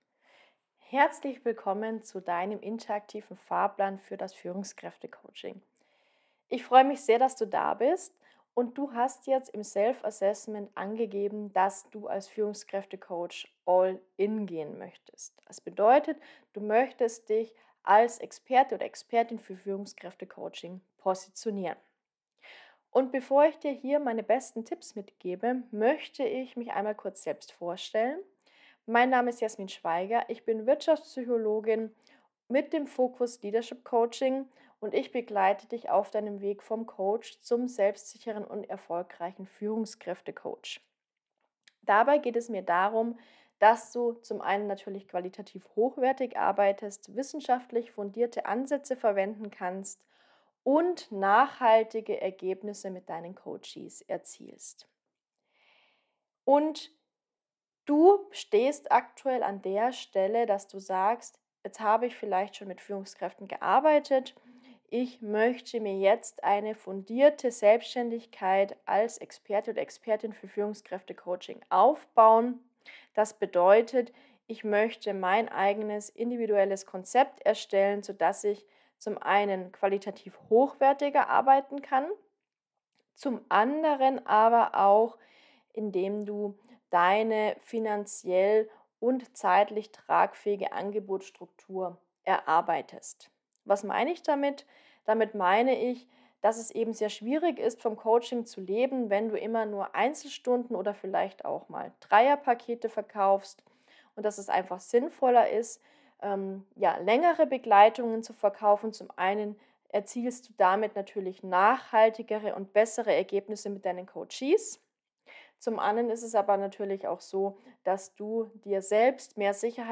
Meine Audionachricht für dich